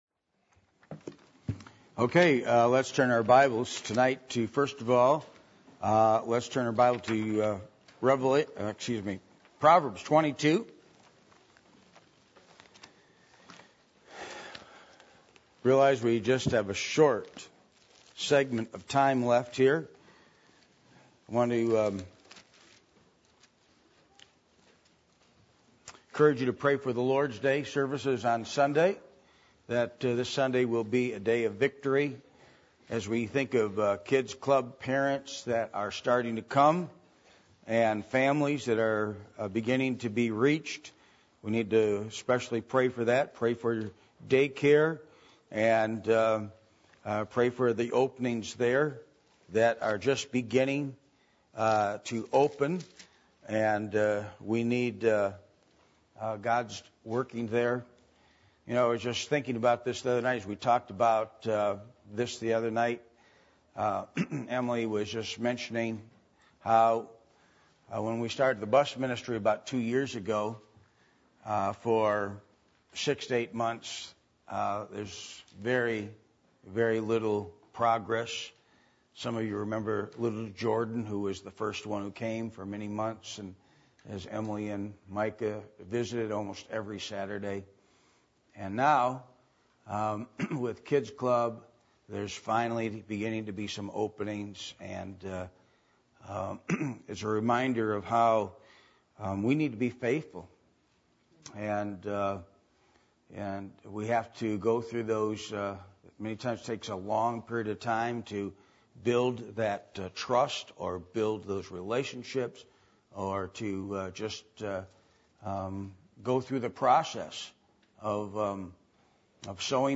Passage: Proverbs 22:28-29 Service Type: Midweek Meeting